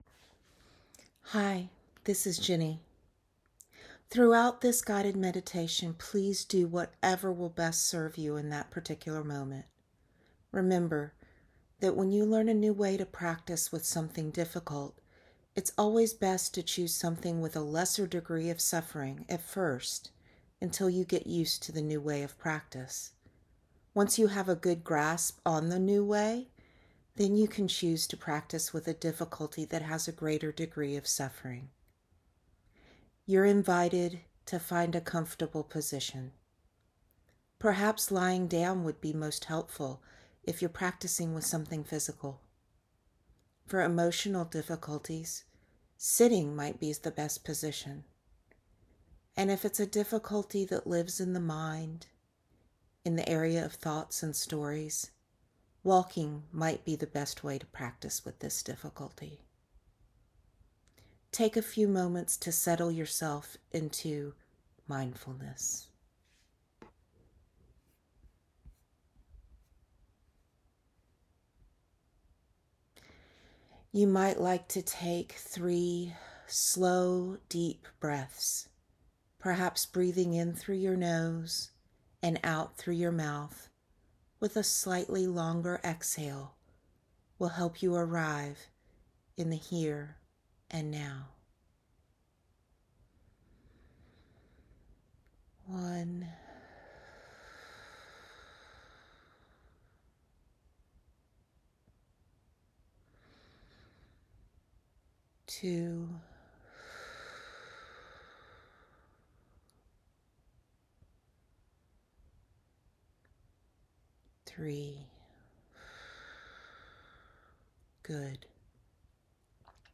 Guided Meditation - Practicing with Difficulties (22:00)